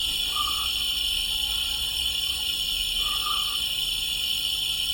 Ethernet Steckdose brummt
• ▲ ▼ Hallo, ich habe hier eine Netio230B, die im eingeschalteten Zustand brummt. Ich habe das Geräusch hier mal aufgenommen, es ist sehr leise, nicht so laut, wie in der Aufnahme.